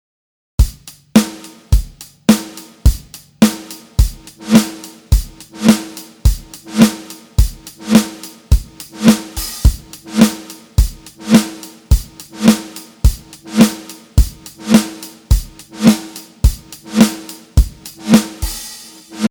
次に、LFO>LPを上げてローパスの音がLFOの影響を受けるように設定しました。
フィルターが開いてる状態からキュッとしまった感じになりましたね。
これをちょっとずらしてスピードを1/8にしてみると….